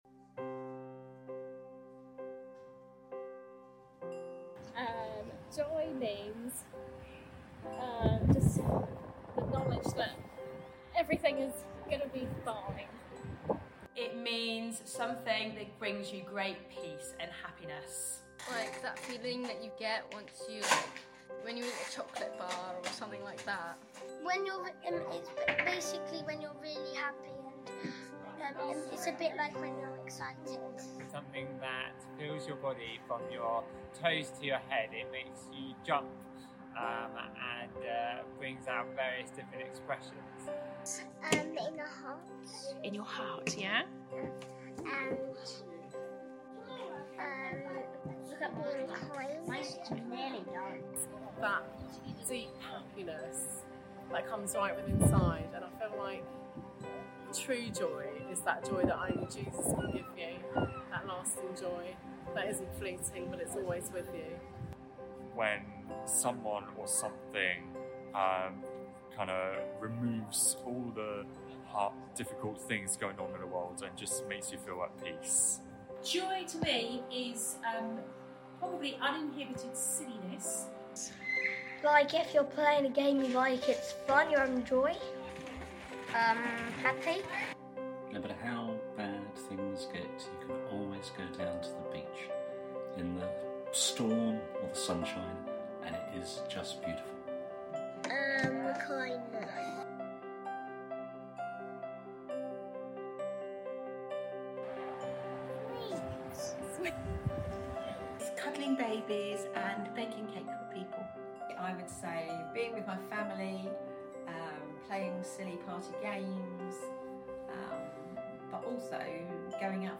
A Live recording of our 2022 Carol Service!